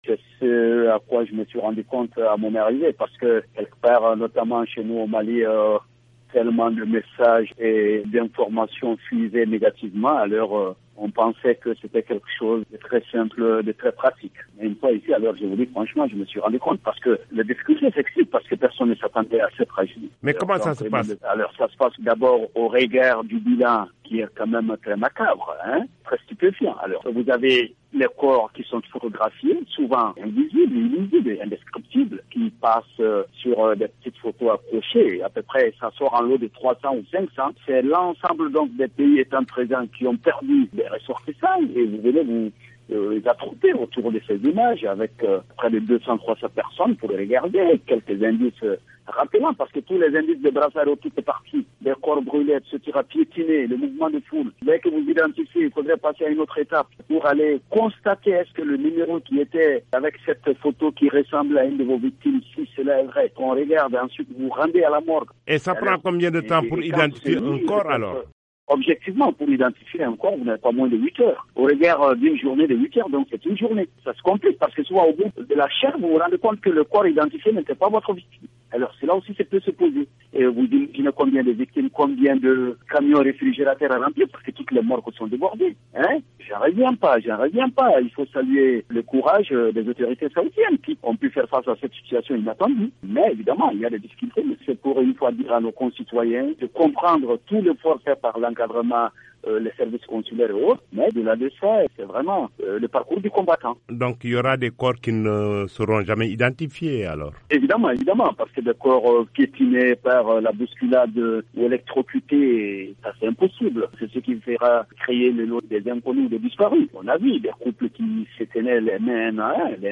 Thierno Ass Diallo, ministre malien du Culte, joint à Mina, en Arabie Saoudite